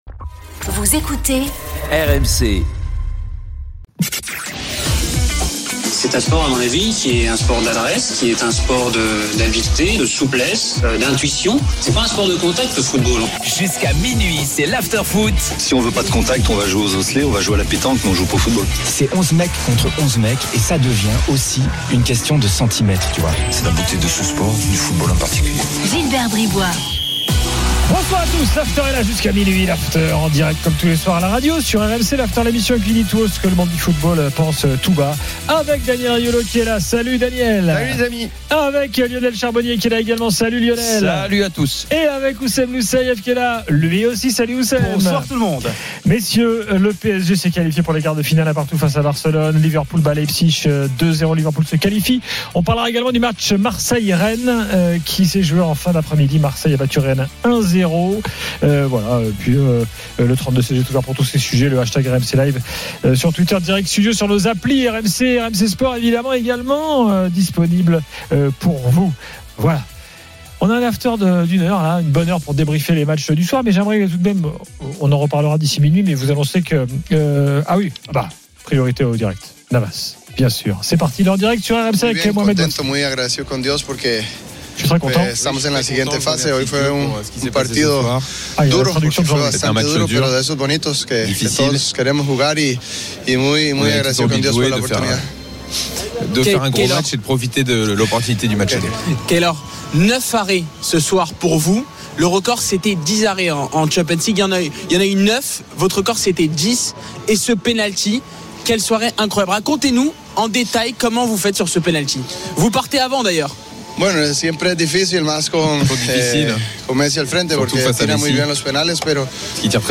Chaque jour, écoutez le Best-of de l'Afterfoot, sur RMC la radio du Sport !
L'After foot, c'est LE show d'après-match et surtout la référence des fans de football depuis 15 ans !
les conférences de presse d'après-match et les débats animés entre supporters, experts de l'After et auditeurs.